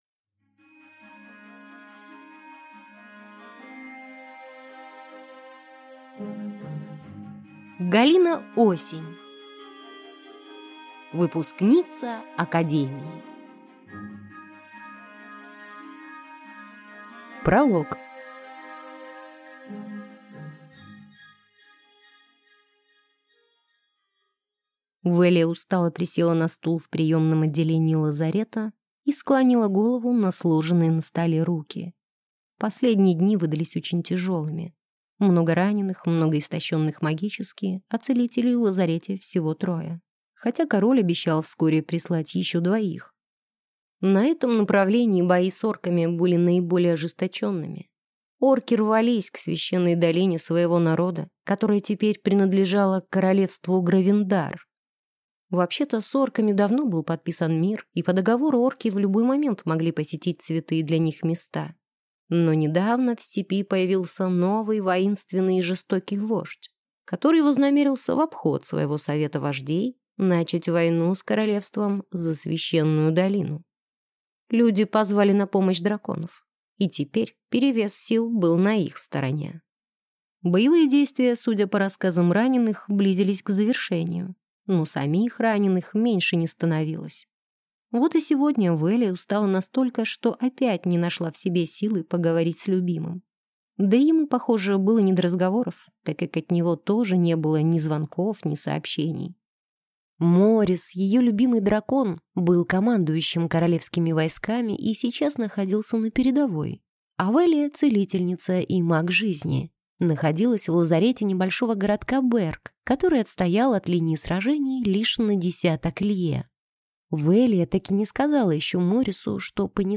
Аудиокнига Выпускница академии | Библиотека аудиокниг